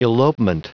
Prononciation du mot : elopement
elopement.wav